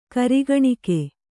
♪ karigaṇike